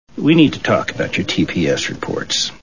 Office Space Movie Sound Bites